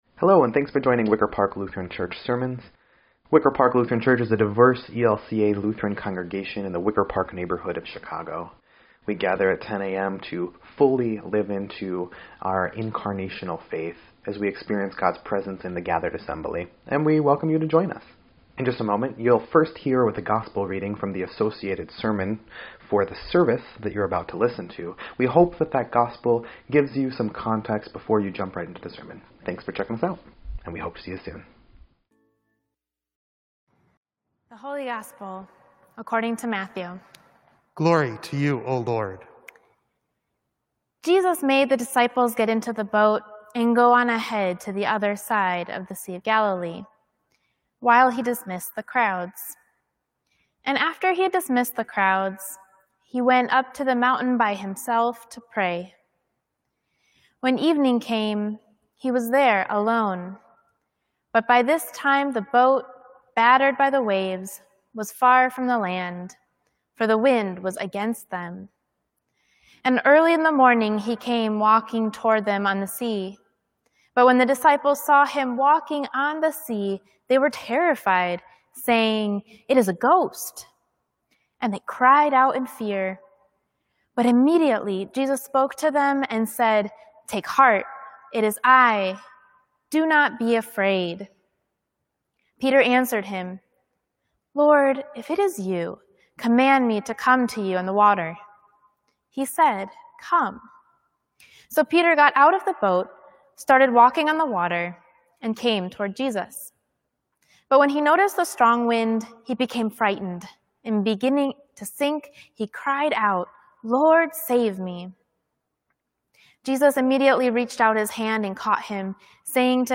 8.9.20-Sermon_EDIT.mp3